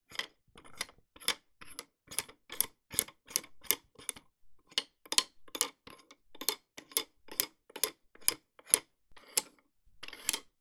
Звуки счётов: Играем с детскими счётами